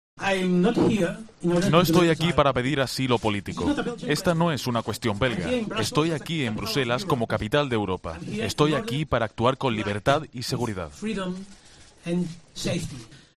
"Nunca abandonaremos el Gobierno y vamos a seguir trabajando", dijo Puigdemont en una comparecencia ante la prensa en Bruselas en la que preguntó al Gobierno de Madrid si respetará el 21 de diciembre los "resultados mayoritarios de las fuerzas independentistas"